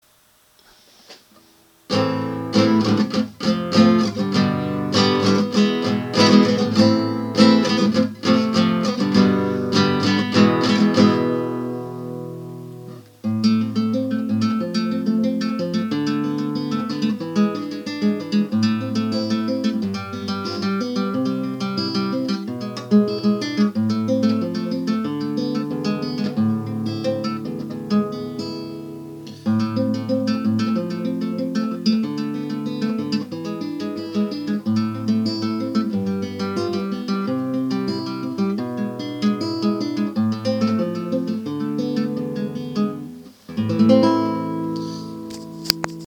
Dictaphone
Ce Z3 ne déroge pas à la règle, c’est mauvais, il y a un souffle bien audible en permanence. On peut enregistrer quelques notes vocales avec mais il faut que le sujet soit très proche du micro car sinon on n’entend rien. Les enregistrements se font uniquement en MP3 128kbps.
Exemple d’enregistrement Voix